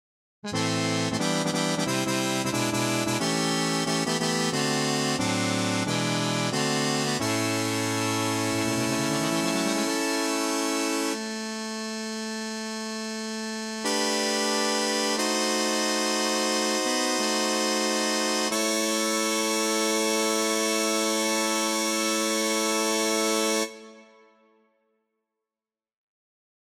Key written in: A Minor
How many parts: 4
Type: Barbershop
All Parts mix: